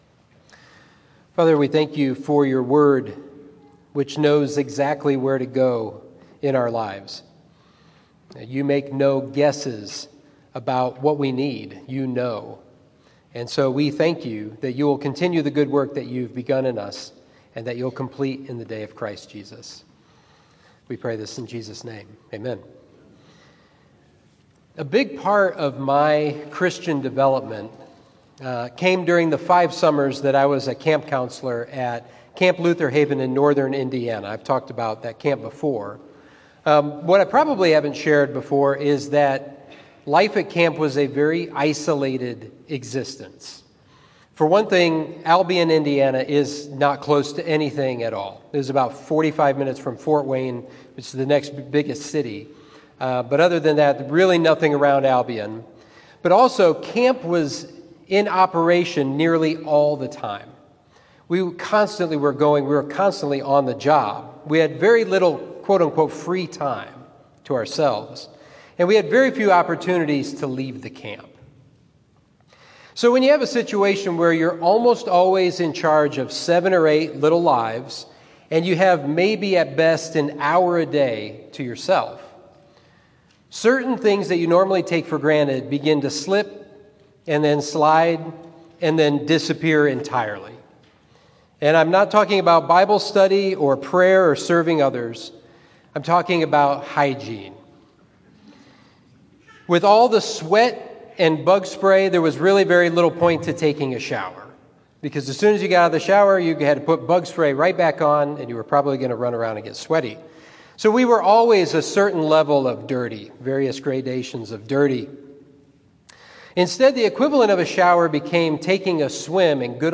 Sermon 2/13: Malachi: Good Enough for God?